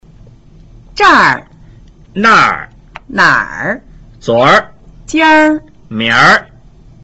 zhr, nr, nr, zur, jnr, mngr